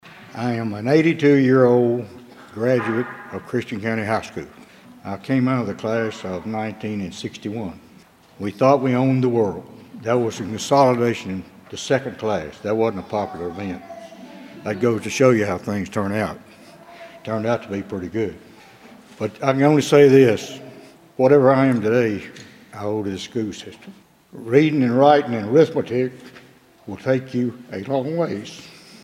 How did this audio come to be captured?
Wednesday night, some employees, their families, and school officials gathered to celebrate several dozen coworkers.